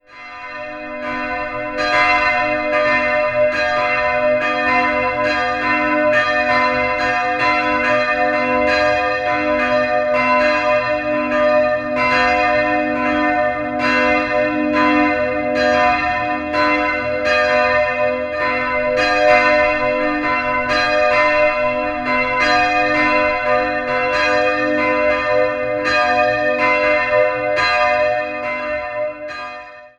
2-stimmiges Geläute: h'-d'' Die beiden Glocken wurden im Jahr 1969 von Friedrich Wilhelm Schilling in Heidelberg gegossen.